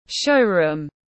Cửa hàng trưng bày tiếng anh gọi là showroom, phiên âm tiếng anh đọc là /ˈʃəʊ.ruːm/.
Showroom /ˈʃəʊ.ruːm/
Showroom.mp3